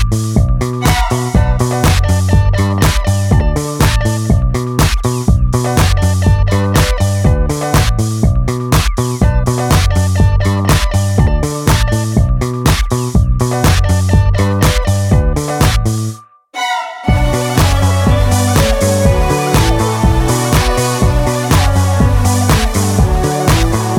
no Backing Vocals Or Vocoder Pop (1990s) 3:08 Buy £1.50